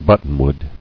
[but·ton·wood]